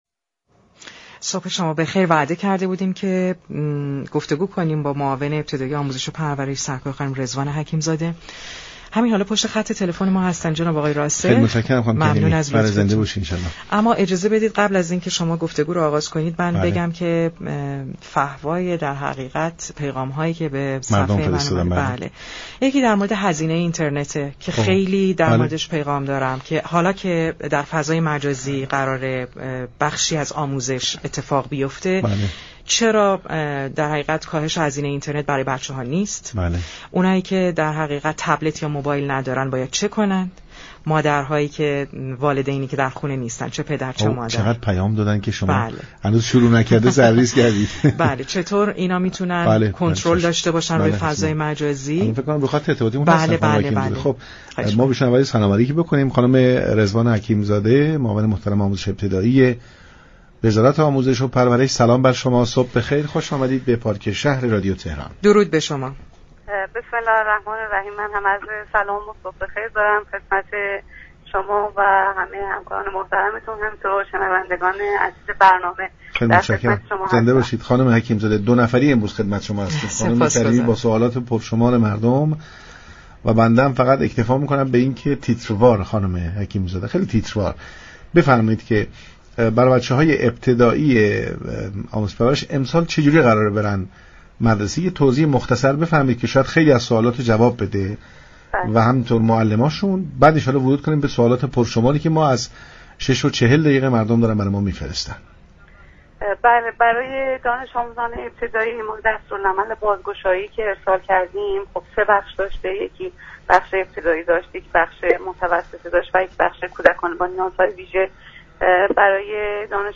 رضوان حكیم زاده، معاون آموزش ابتدایی وزارت آموزش و پرورش، اقدامات آموزش و پرورش در مقطع ابتدایی برای سال تحصیلی جدید را در برنامه پارك شهر تشریح كرد.